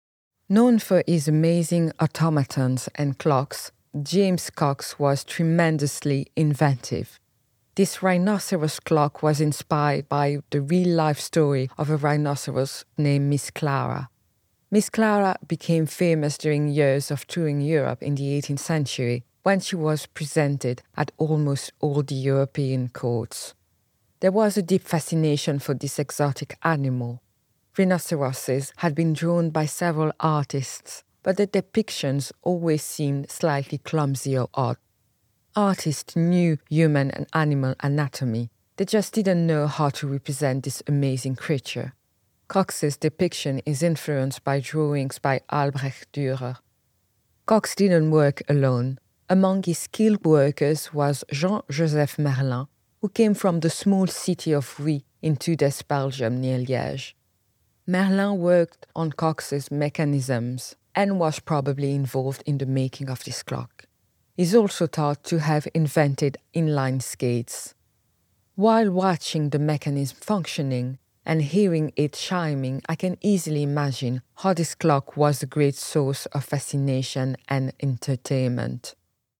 Curator's Reflection (English)